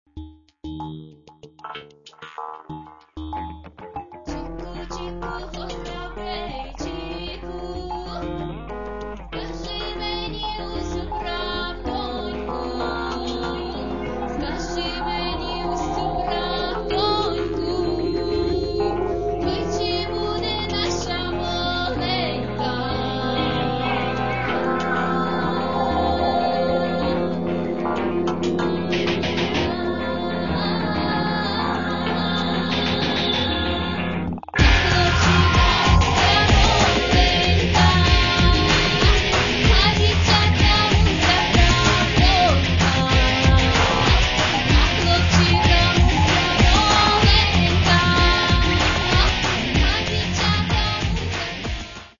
Catalogue -> Rock & Alternative -> Electronic Alternative